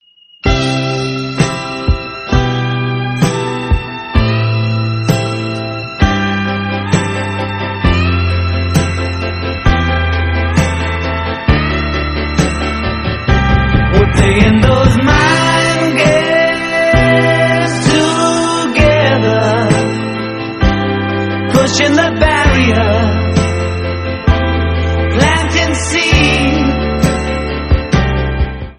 Rock, Pop, Punk, New Wave, Classic Rock